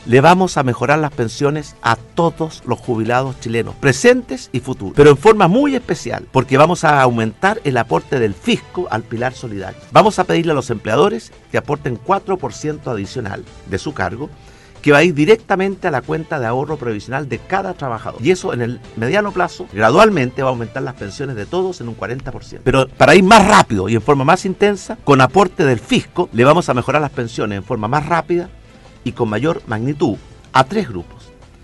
Agradeciendo en Osorno el cariño de cientos de personas que ayer llegaron a respaldar con un banderazo su postulación presidencial, el candidato por el bloque Chile Vamos, Sebastián Piñera, se dirigió a la audiencia de Radio Sago y en una entrevista en profundidad, abordó respaldado por propuestas, temas relevantes como su compromiso con la clase media y los jubilados.